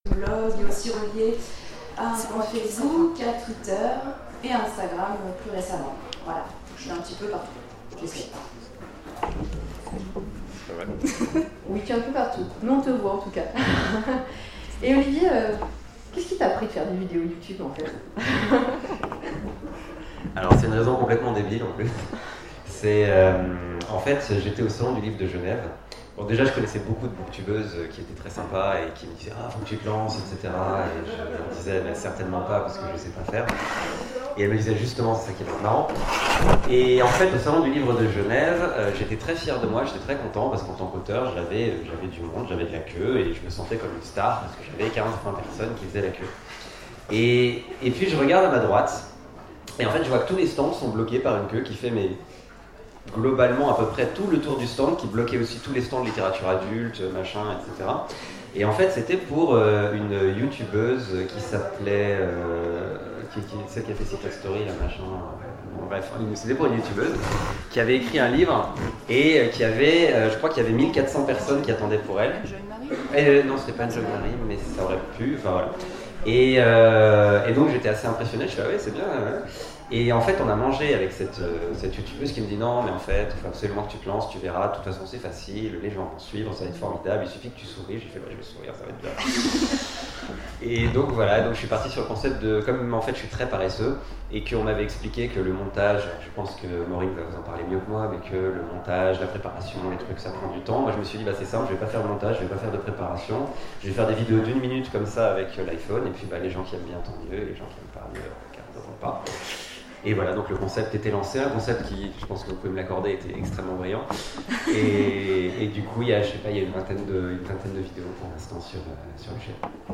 Aventuriales 2017 : Conférence Booktube
Aventuriales_2017_table_ ronde_les_booktubeurs_ok.mp3